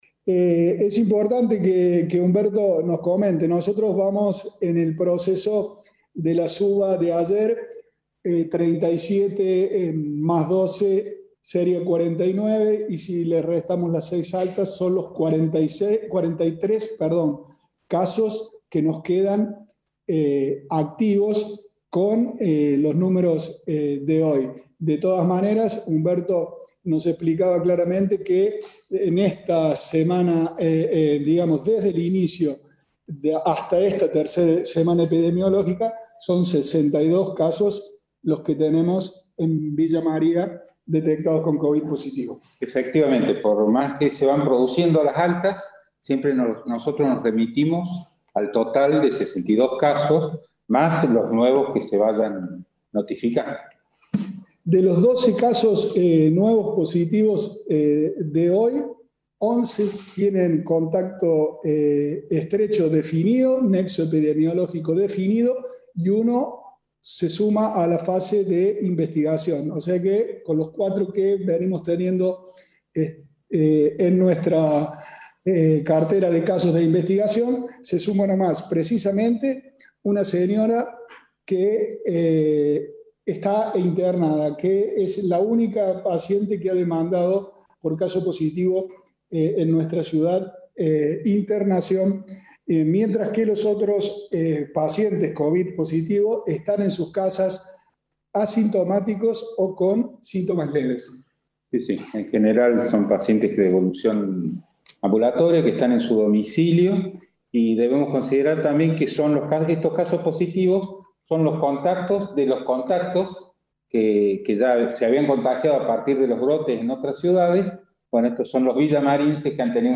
El intendente interino Pablo Rosso y el secretario de Salud Humberto Jure, brindaron detalles en el informe de cada noche.